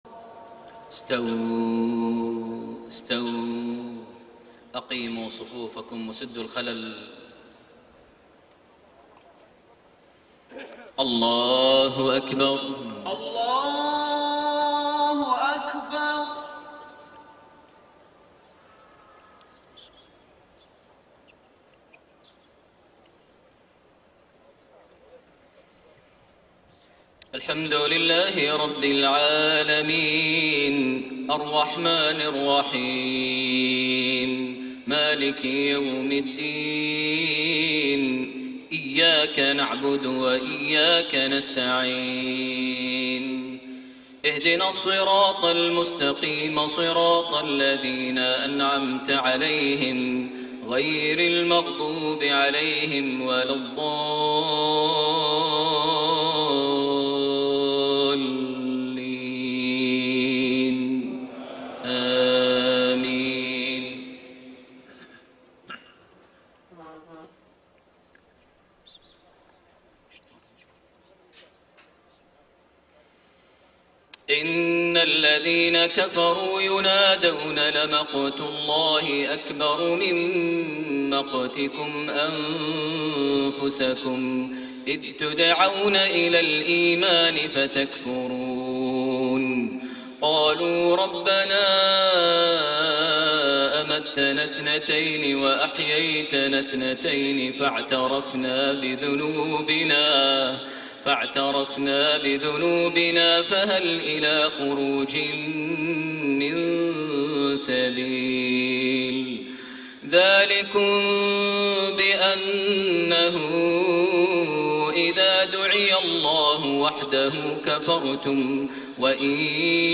Maghrib prayer from Surah Ghaafir > 1433 H > Prayers - Maher Almuaiqly Recitations